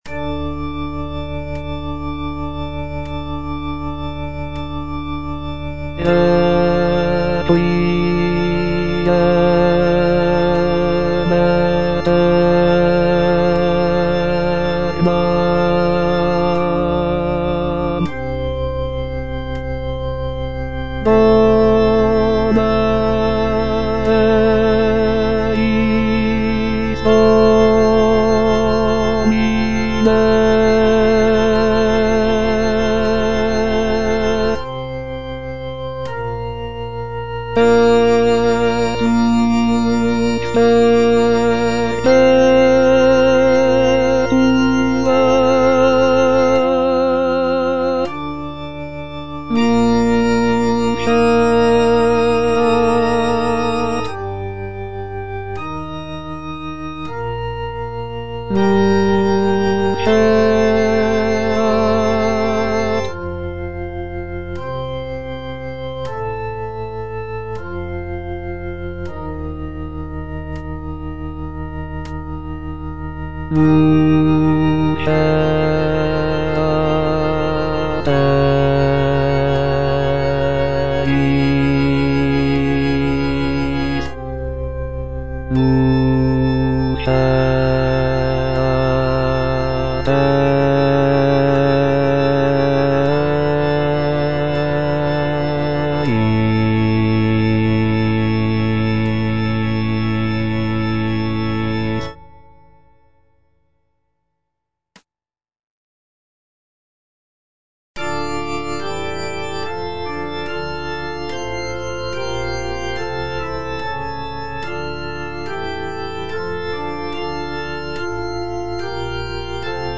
G. FAURÉ - REQUIEM OP.48 (VERSION WITH A SMALLER ORCHESTRA) Introït et Kyrie (bass I) (Voice with metronome) Ads stop:
This version features a reduced orchestra with only a few instrumental sections, giving the work a more chamber-like quality.